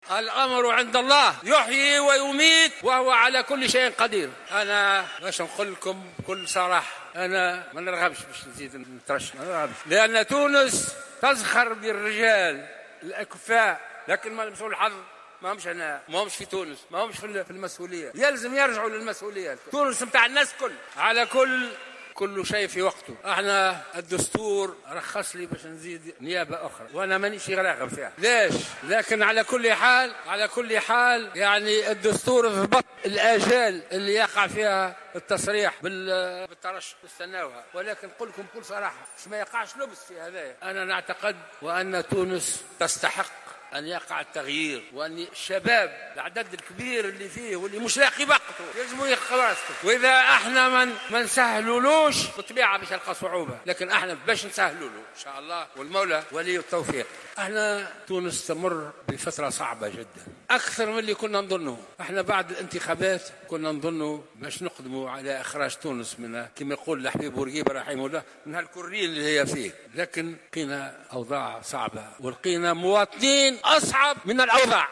وأضاف خلال كلمة ألقاها في افتتاح أشغال المؤتمر الانتخابي الاول لنداء تونس بالمنستير، وبثتها صفحة رئاسة الجمهورية مباشرة، أن "كل شيء في وقته" وسينتظر آجال تقديم ملفات الترشح التي تضبطها الهيئة العليا المستقلة للانتخابات".